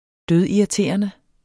Udtale [ ˈdøð- ]